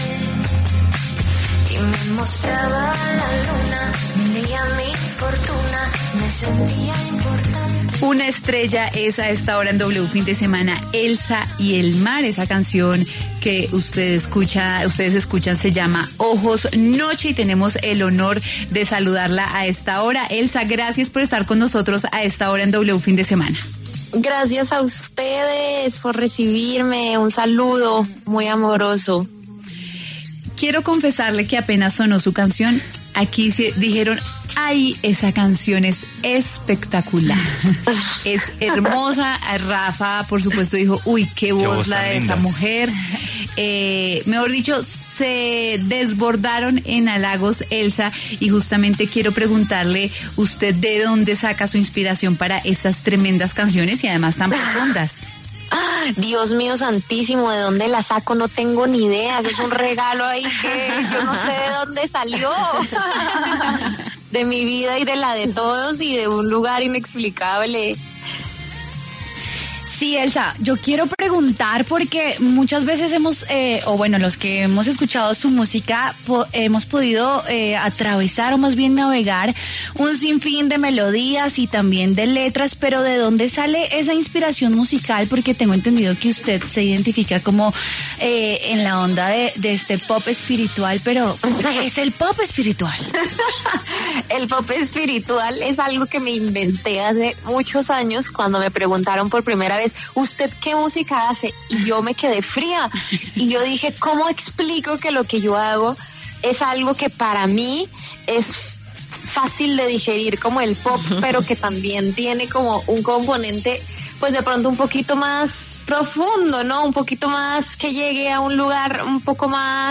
La cantautora Elsa y Elmar habló en W Fin de Semana acerca de su nuevo álbum ‘Palacio Deluxe’ y el desafío de sostener una carrera distinta dentro de la industria musical.